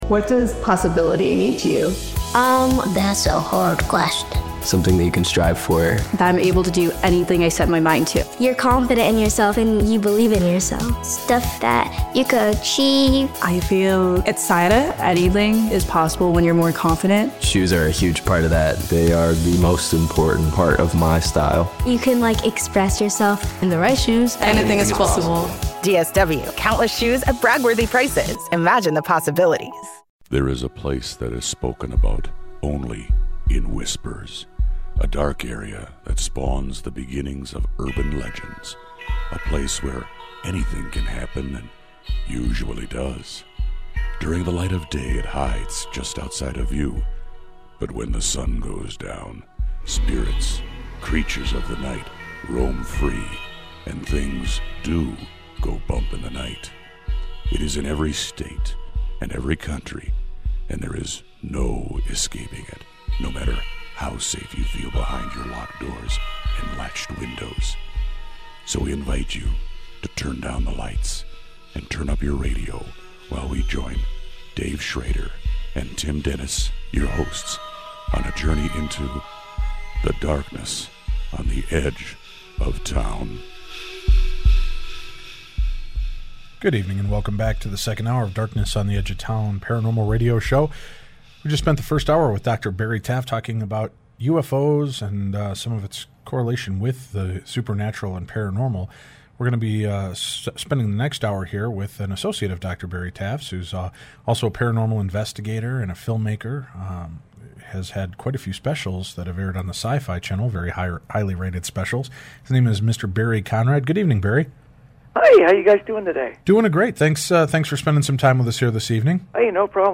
paranormal radio show